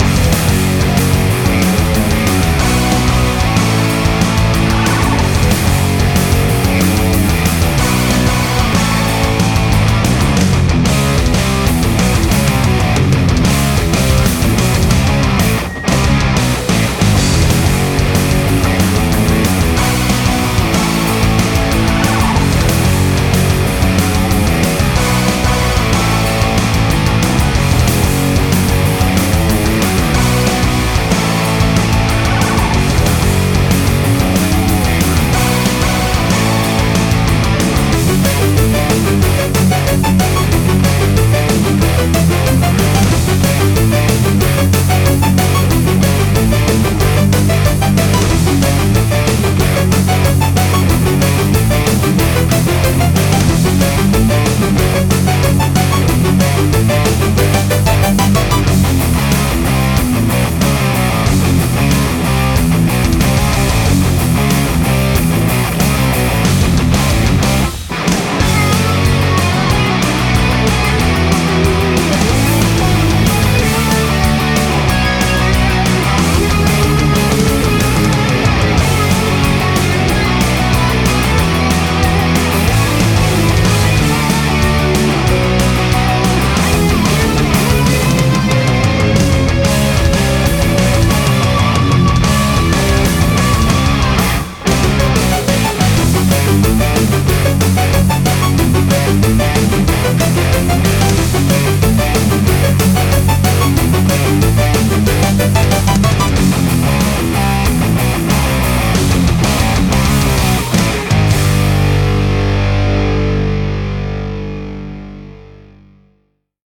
BPM185--1
Audio QualityPerfect (High Quality)